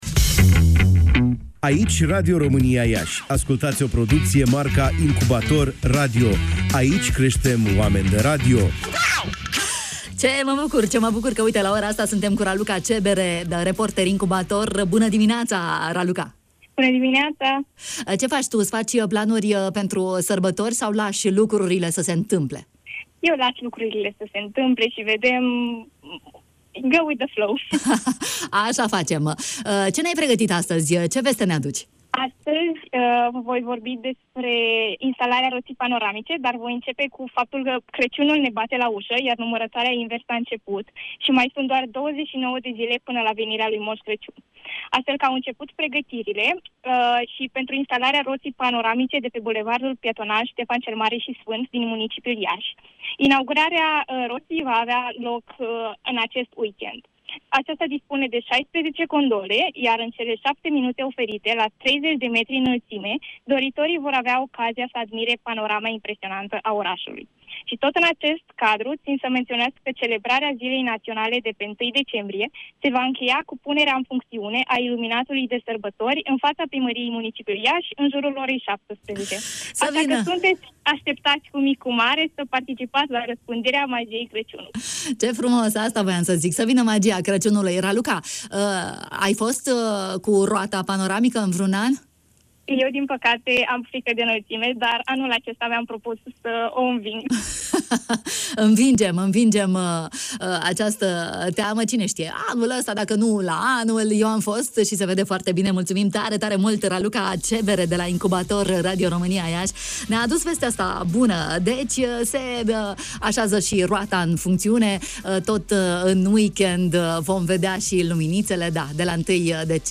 De când putem vedea Iașul din roata panoramică? Am aflat astăzi, în matinalul Radio România Iași